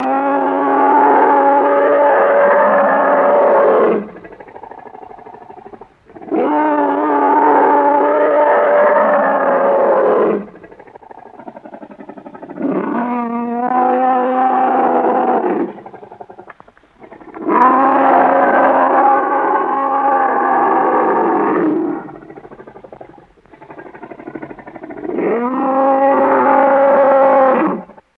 Monster Roars Groans x5 Mono